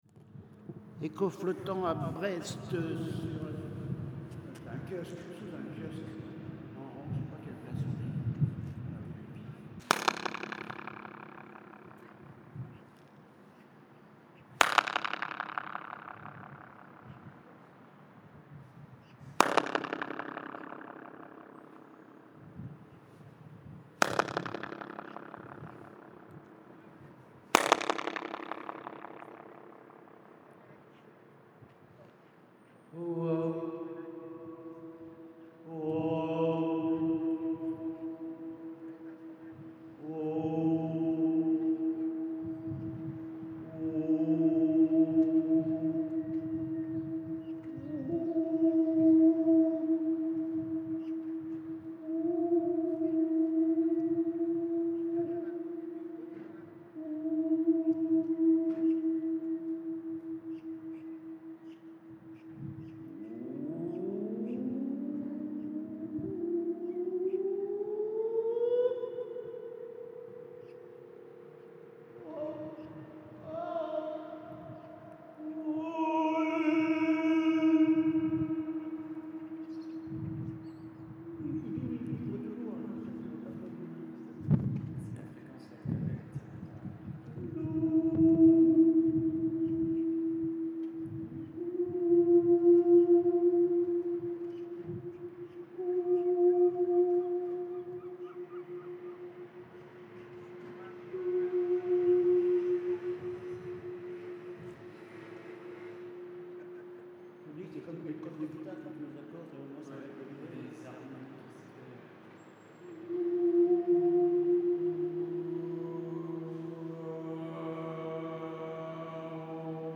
Dans un petit kiosque à musique à Brest
Dans ces deux derniers exemples vient se mêler à l’effet d’écho flottant un effet de filtrage.
Dans l’exemple du kiosque, l’expérience se poursuit au-delà de l’écho flottant, vers une étude de la fréquence de résonance d’un espace.
FX_echo_flottant_kiosque_Brest.mp3